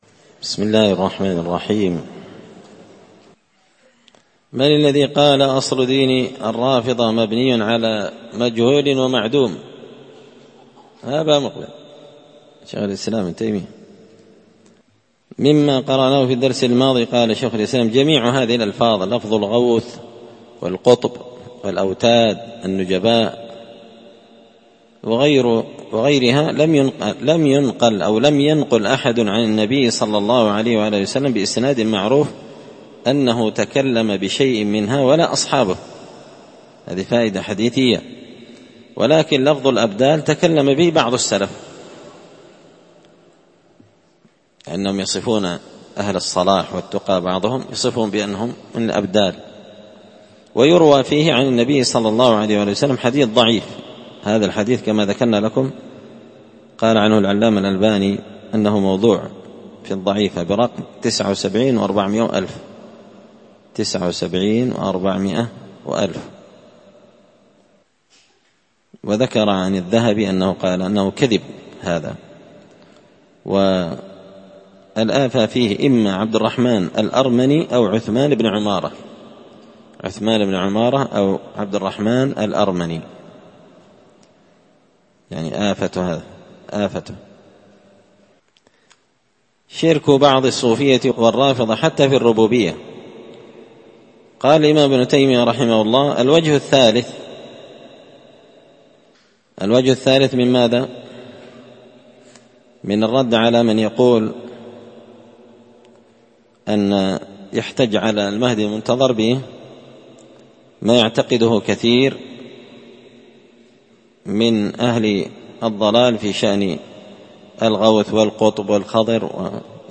الدرس السابع عشر (17) فصل شرك بعض الصوفية والرافضة حتى في أمور الدين